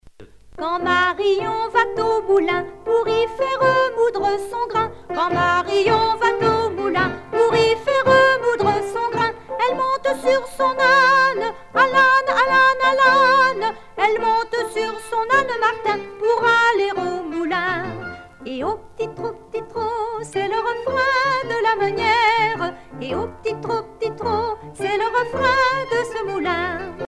rondes enfantines
Pièce musicale éditée